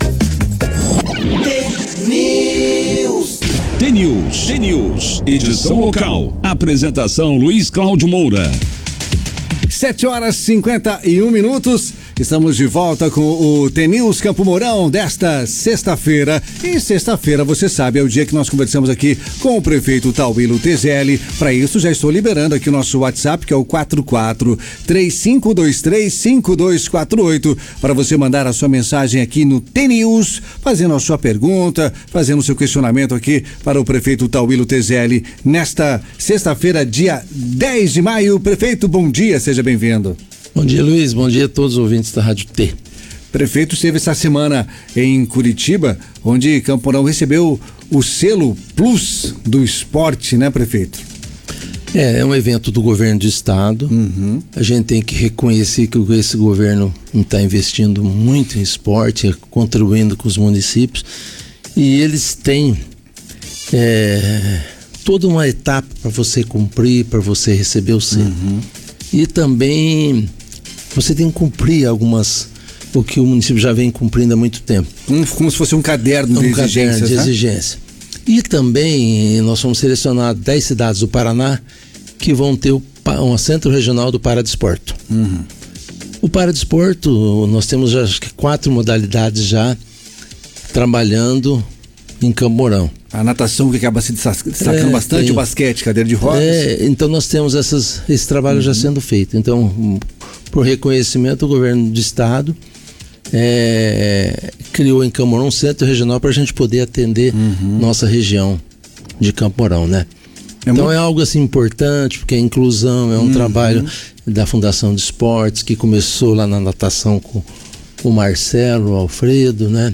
O jornal T News, da Rádio T FM, contou, nesta sexta-feira (10), com a participação de Tauillo Tezelli, atual prefeito de Campo Mourão.
Prefeito-Tauillo-na-Radio-T-FM.mp3